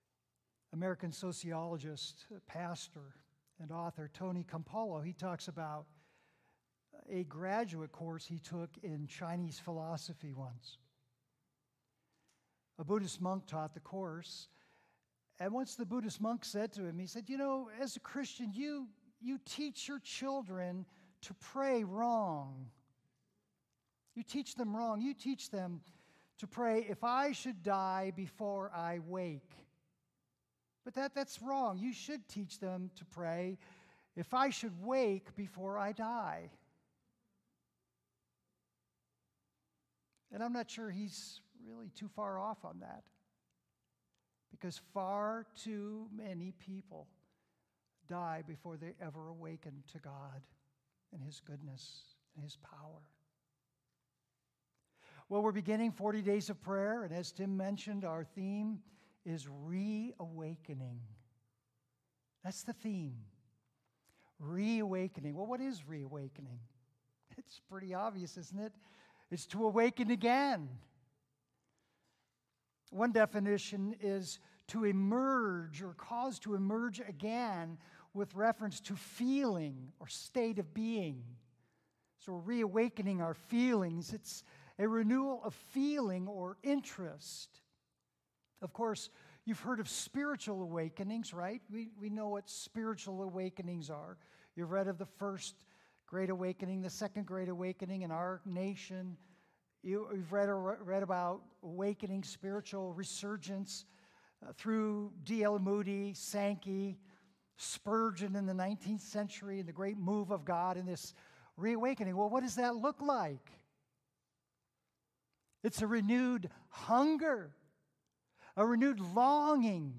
Previous Sermons 40 Days of Prayer - Reawakening to the Glory of Christ